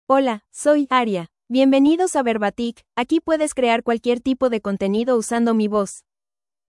Aria — Female Spanish (United States) AI Voice | TTS, Voice Cloning & Video | Verbatik AI
FemaleSpanish (United States)
Voice sample
Listen to Aria's female Spanish voice.
Female
Aria delivers clear pronunciation with authentic United States Spanish intonation, making your content sound professionally produced.